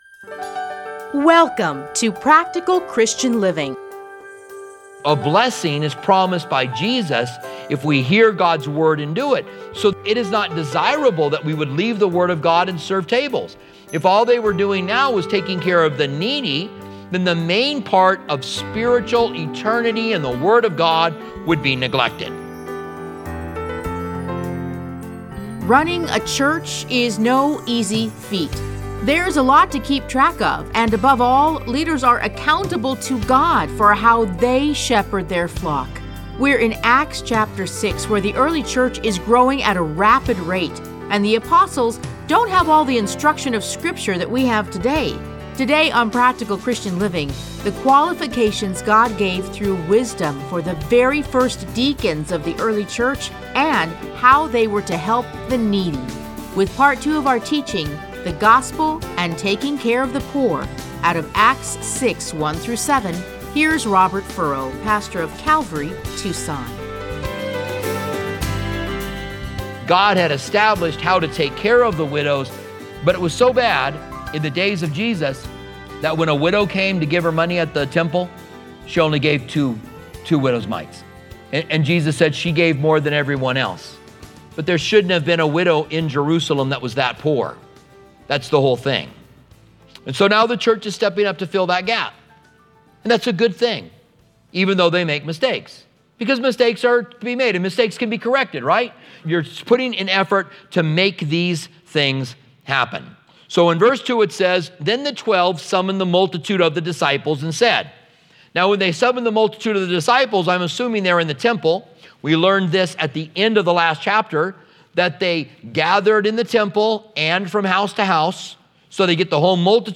Listen to a teaching from Acts 6:1-7.